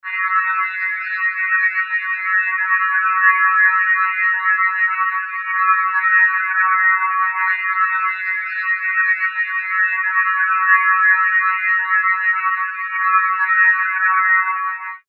Gemafreie Sounds: Kreaturen
mf_SE-4215-alien_3.mp3